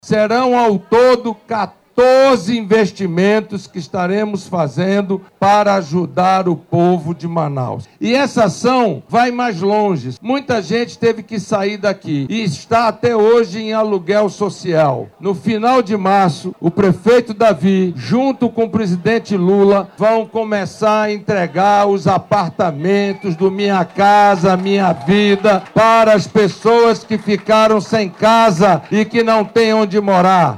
O senador Eduardo Braga afirmou que os recursos fazem parte de 14 investimentos para a capital e citou a entrega de moradias.
SONORA-1-EDUARDO-BRAGA.mp3